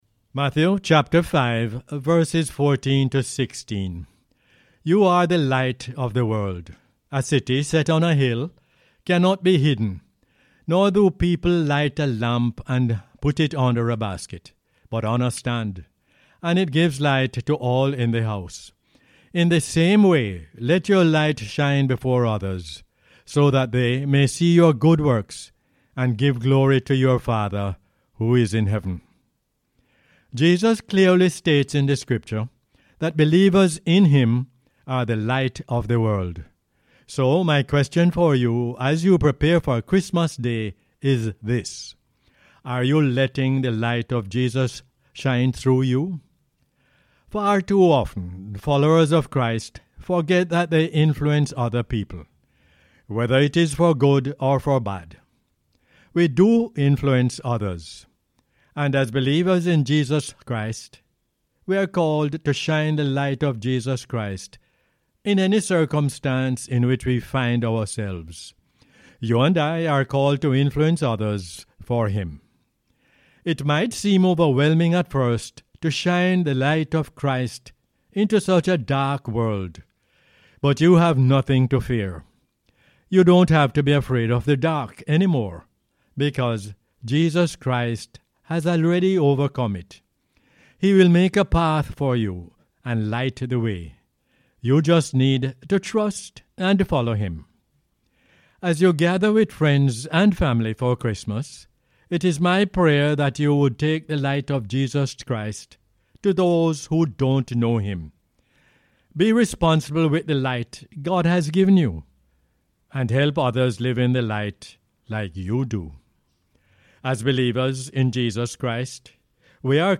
Matthew 5:14-16 is the "Word For Jamaica" as aired on the radio on 23 December 2022.